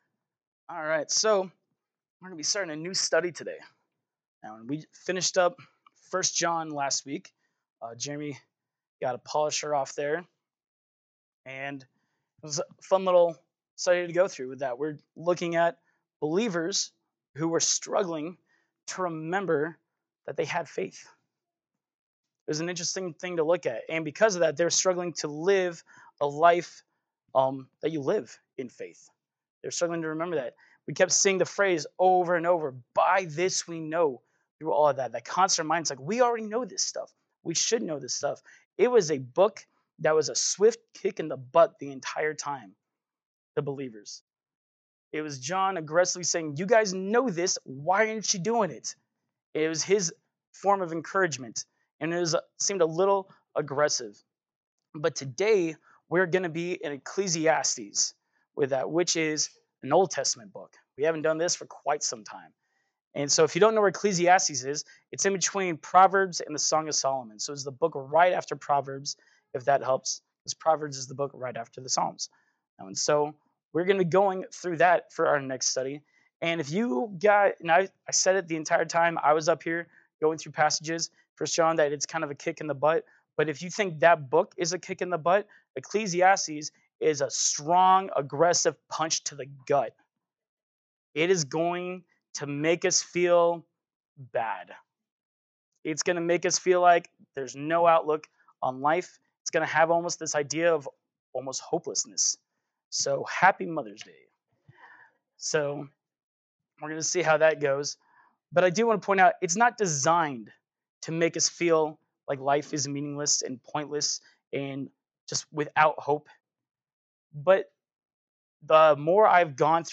Service Type: Sunday Morning Worship